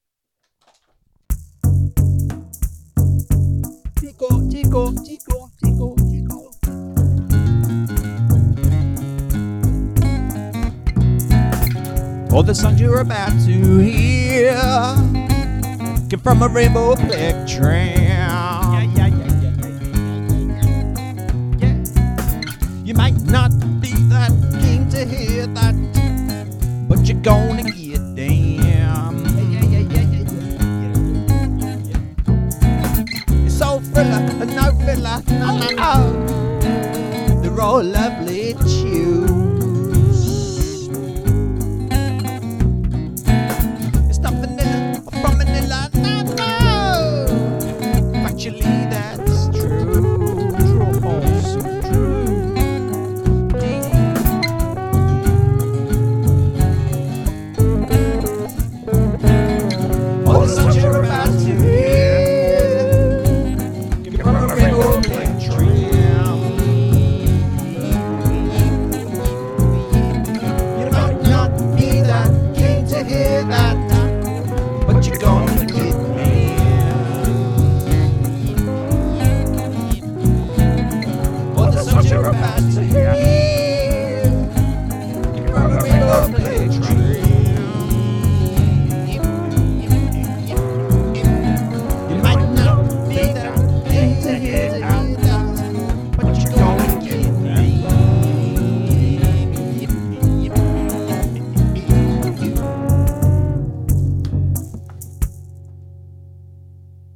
Baby rattle